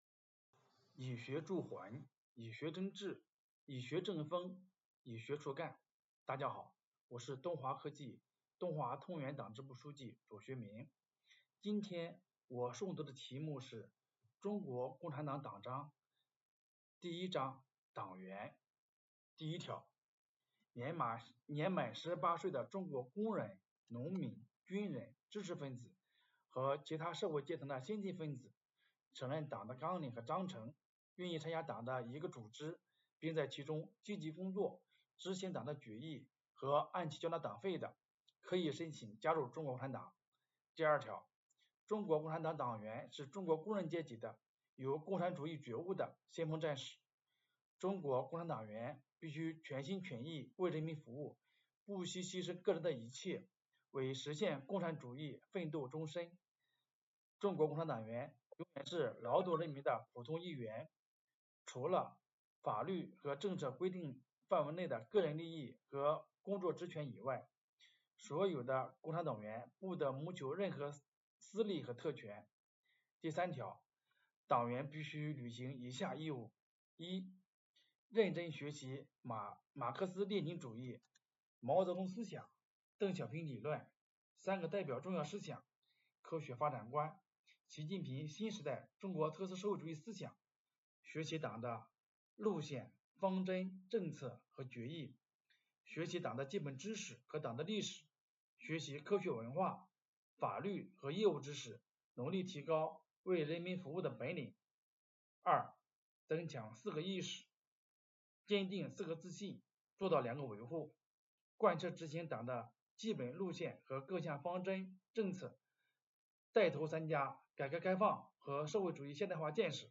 诵读人